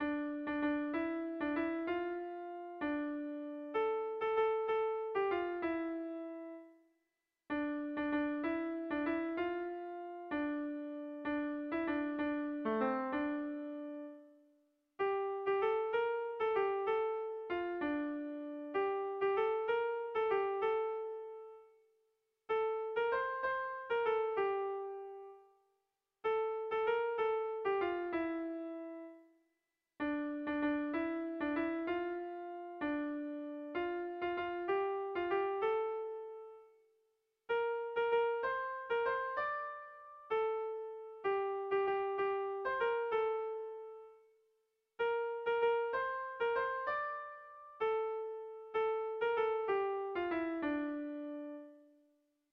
A1A2B